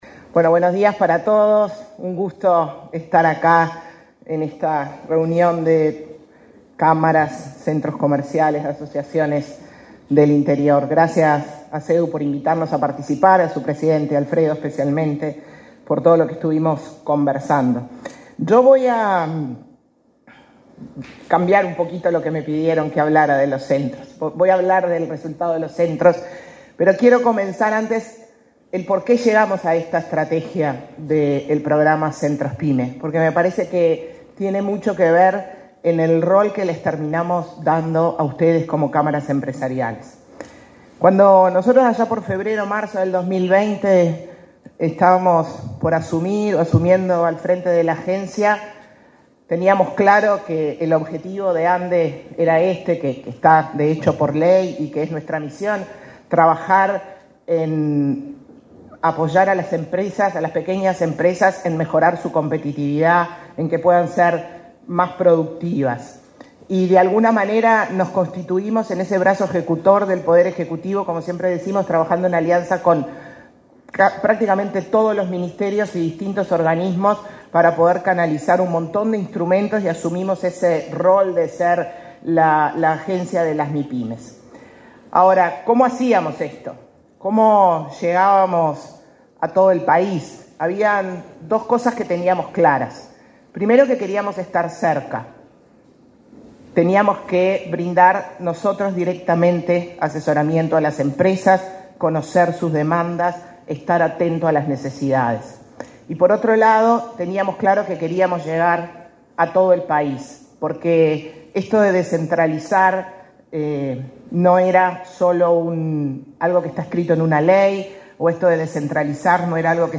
Palabras de la presidenta de la ANDE, Carmen Sánchez
Palabras de la presidenta de la ANDE, Carmen Sánchez 21/06/2024 Compartir Facebook X Copiar enlace WhatsApp LinkedIn El presidente de la República, Luis Lacalle Pou, participó, este 21 de junio, del encuentro de asociaciones y centros comerciales del interior del país, en Paso de los Toros. En el evento disertó la presidenta de la Agencia Nacional de Desarrollo (ANDE), Carmen Sánchez.